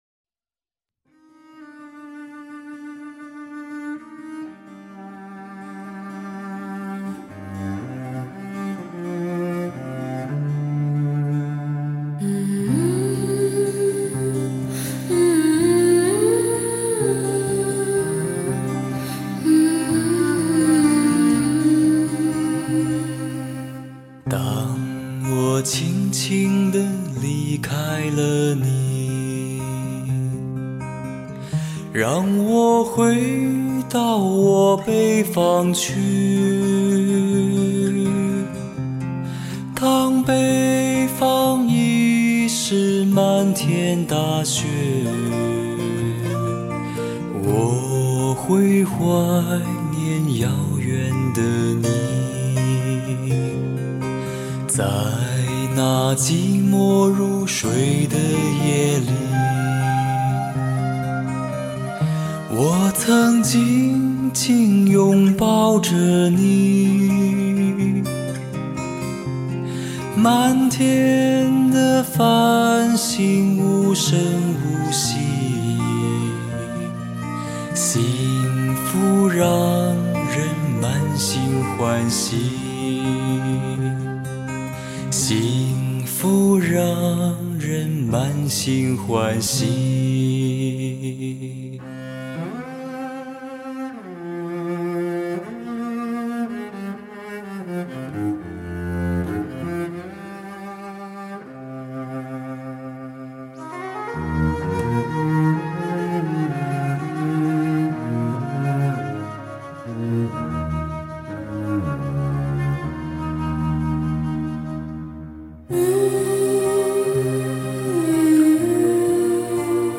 主要是测试人声